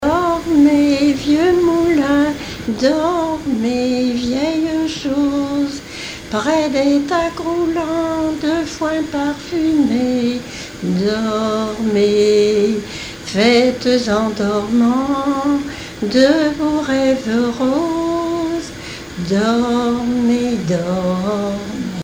berceuse
Genre strophique
Témoignages et chansons
Pièce musicale inédite